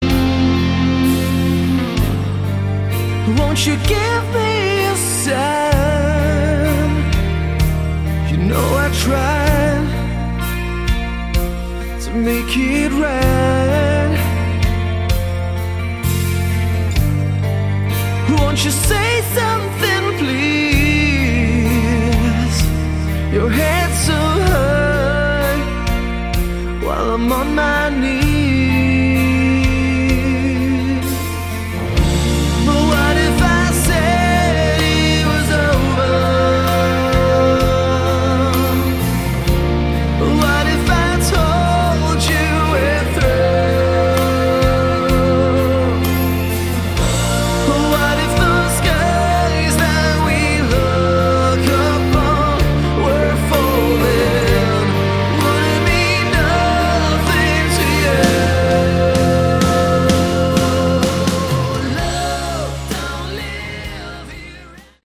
Category: Hard Rock
vocals
drums
bass guitar